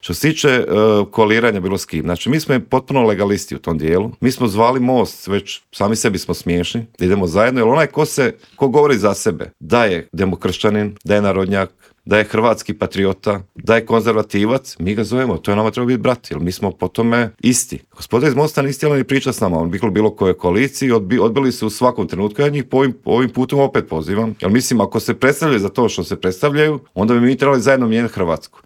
ZAGREB - U Intervjuu Media servisa gostovao je Mario Radić iz Domovinskog pokreta koji se osvrnuo na optužbe premijera Andreja Plenkovića da iza prosvjeda svinjogojaca stoji upravo njegova stranka, otkrio nam s kim bi DP mogao koalirati nakon parlamentarnih izbora, a s kim nikako i za kraj rezimirao 2023. godinu koja lagano ide kraju.